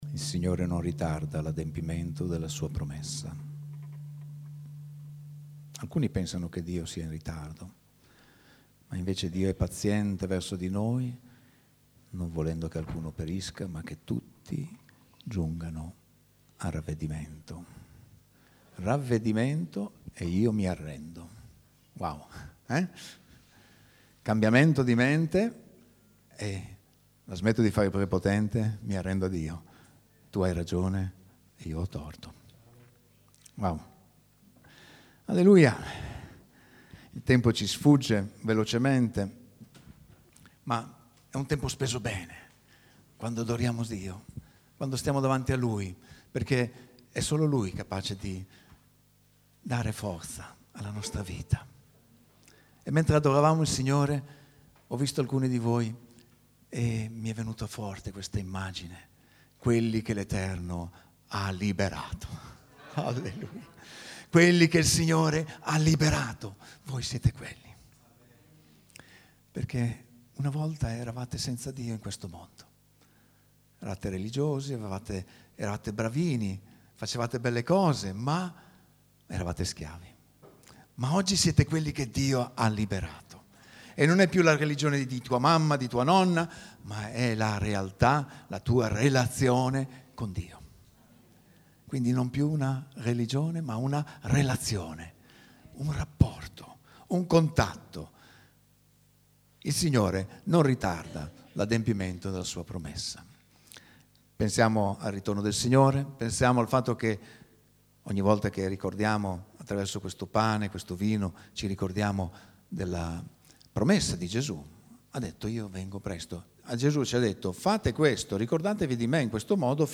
› Pubblicato in Messaggio domenicale